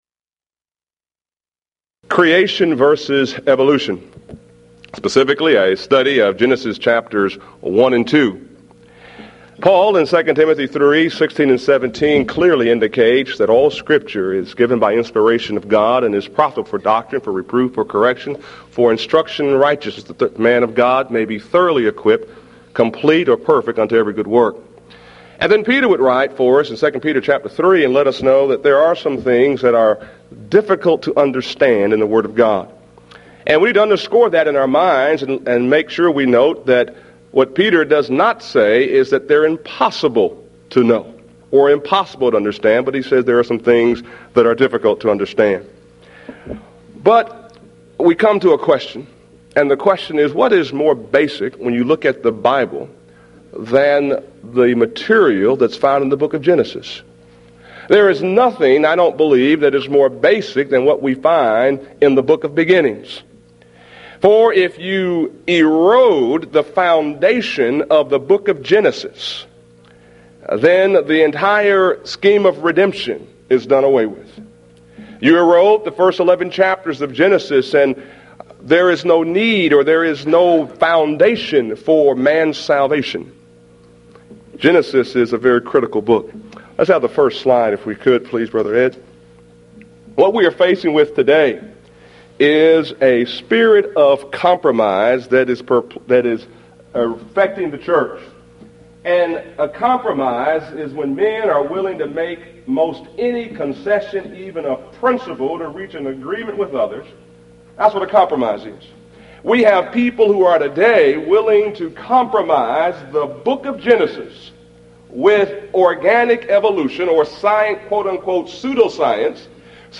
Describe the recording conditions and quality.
Series: Mid-West Lectures Event: 1995 Mid-West Lectures